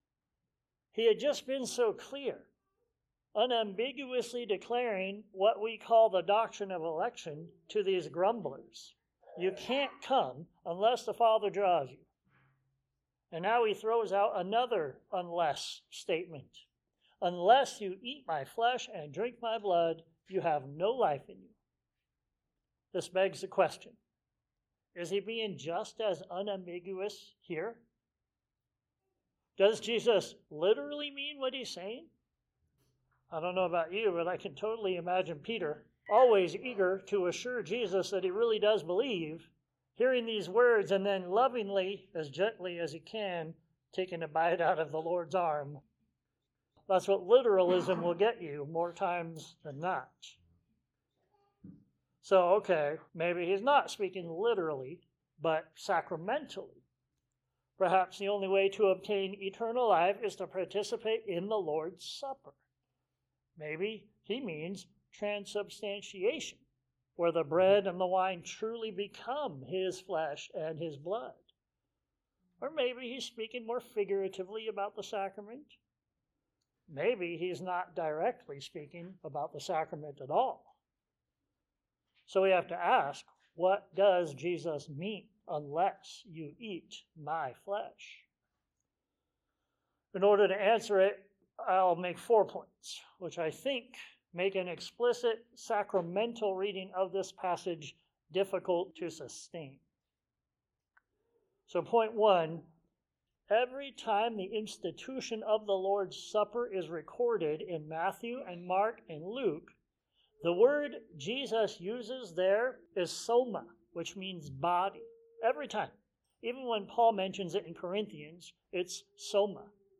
Eat to Live John 6:47-71 Sermons Share this: Share on X (Opens in new window) X Share on Facebook (Opens in new window) Facebook Like Loading...